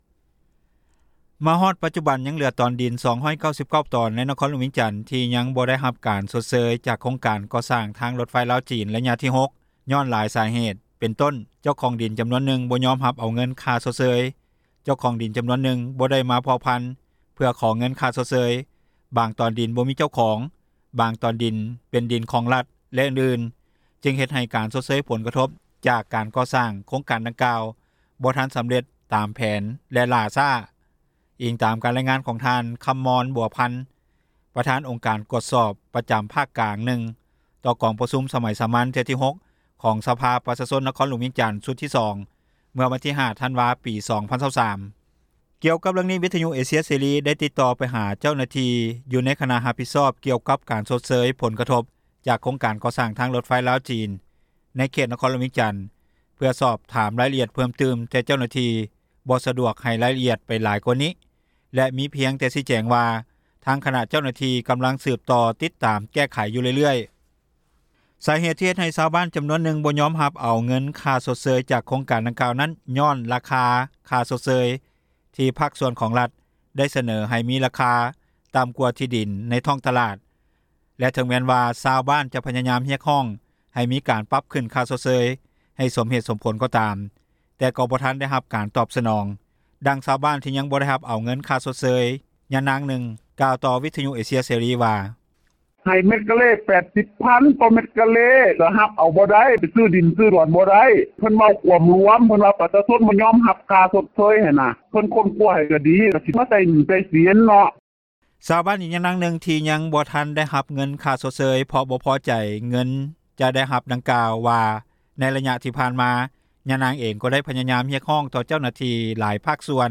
ດັ່ງຊາວບ້ານທີ່ຍັງບໍ່ໄດ້ຮັບເອົາເງິນຄ່າຊົດເຊີຍ ຍານາງນຶ່ງກ່າວຕໍ່ວິທຍຸເອເຊັຽເສຣີວ່າ: